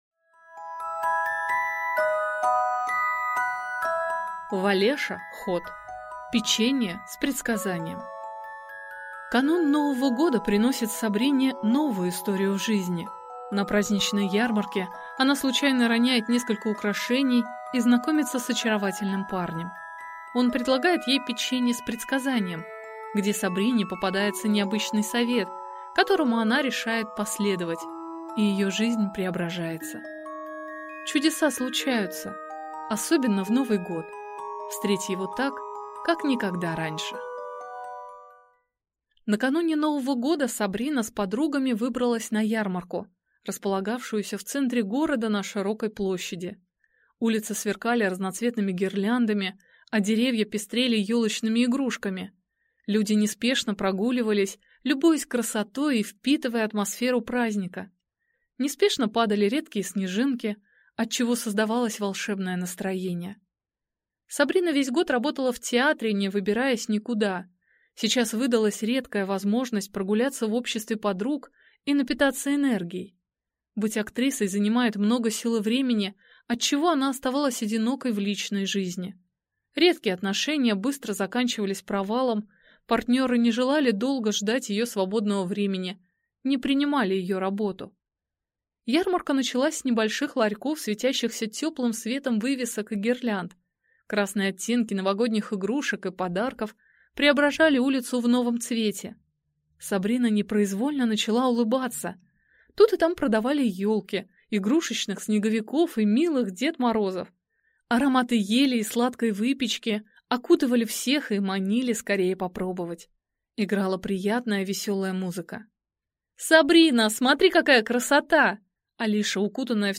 Аудиокнига Печенье с предсказанием | Библиотека аудиокниг